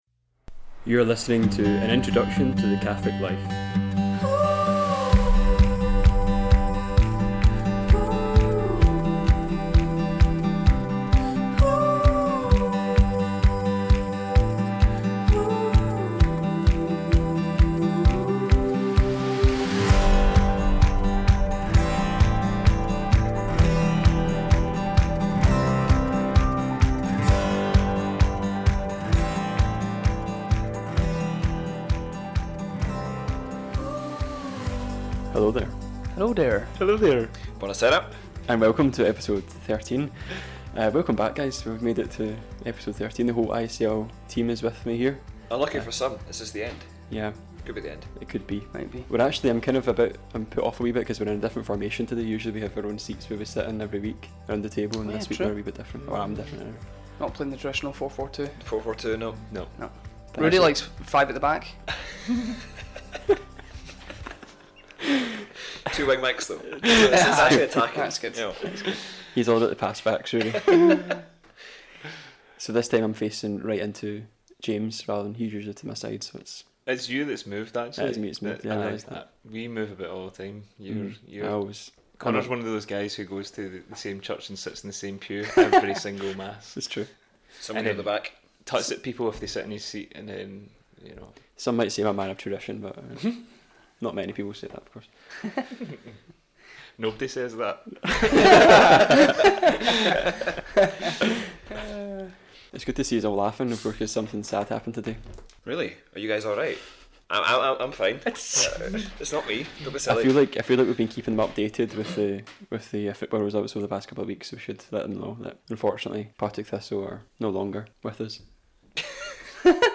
This week the ICL team speak about a central topic in the living of the Catholic Life.